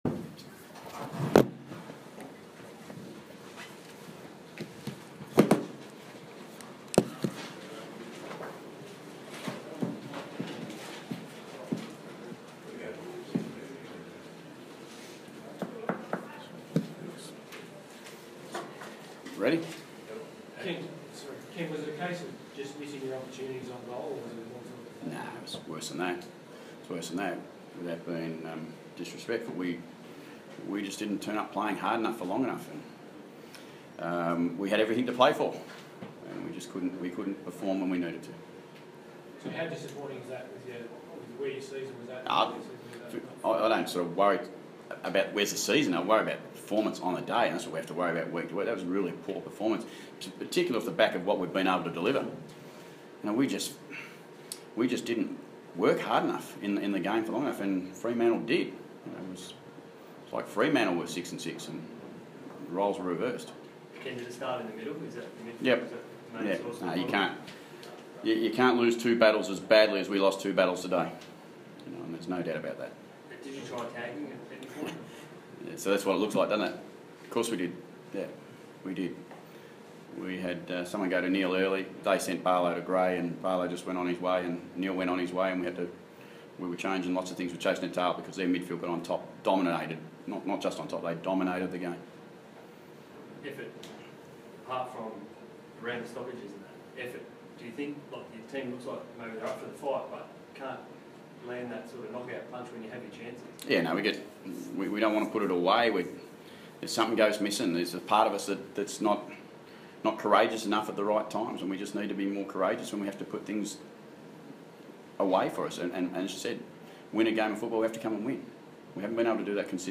Ken Hinkley Post-match Press Conference - Saturday, 18 June, 2016